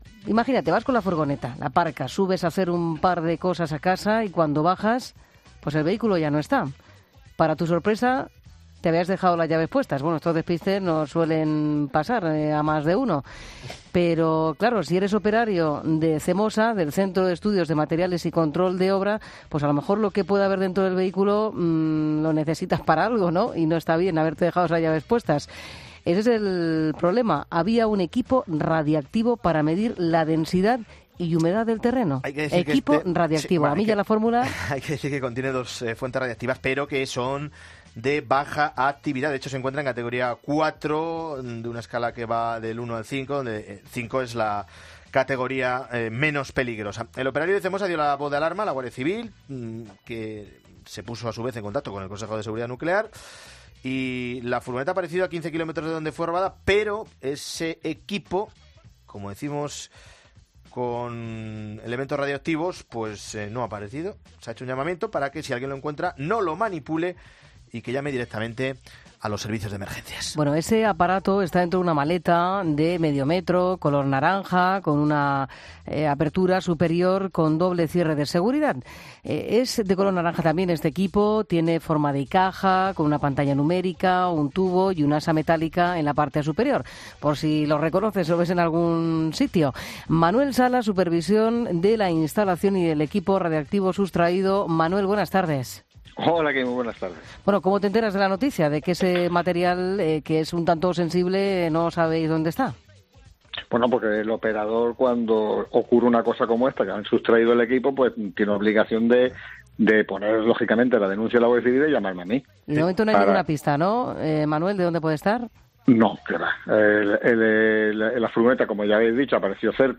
El aparato está dentro de una maleta de medio metro y color naranja con una apertura superior con doble cierre de seguridad.